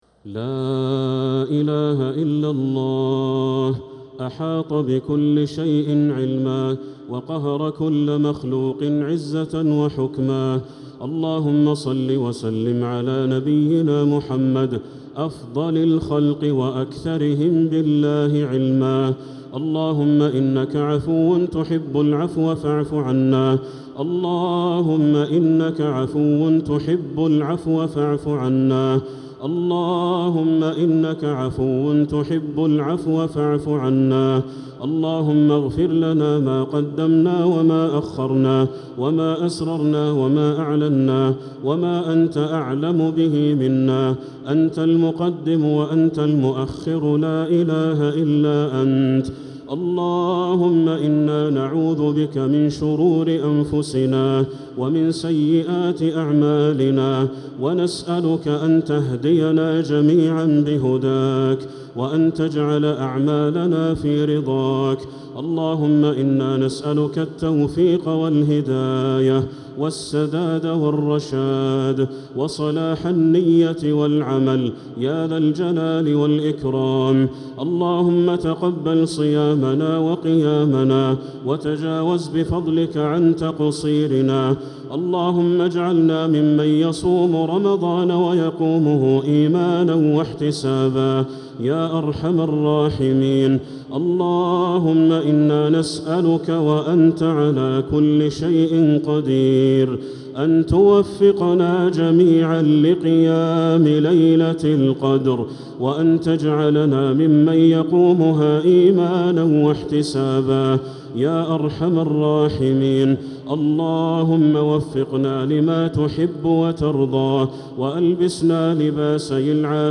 دعاء القنوت ليلة 18 رمضان 1446هـ | Dua 18th night Ramadan 1446H > تراويح الحرم المكي عام 1446 🕋 > التراويح - تلاوات الحرمين